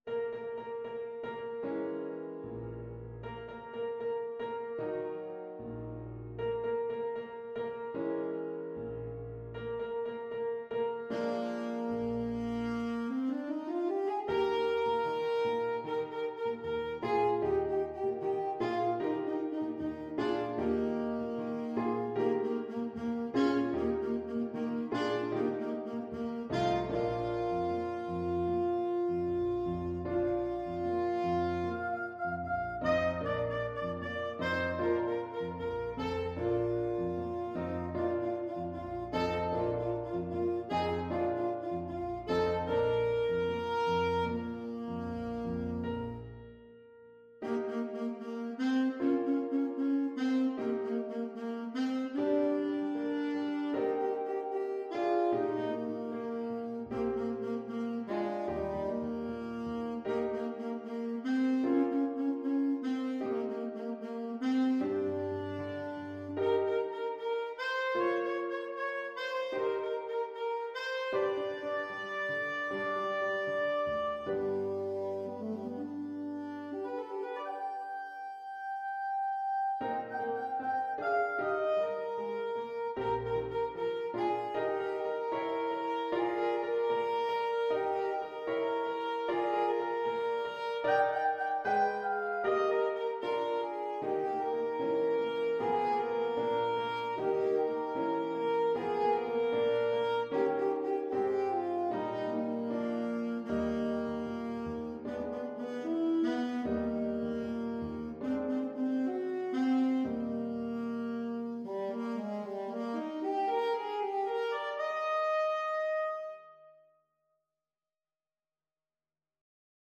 Alto Saxophone version
Alto Saxophone
=76 Allegretto lusinghiero =104
2/4 (View more 2/4 Music)
Classical (View more Classical Saxophone Music)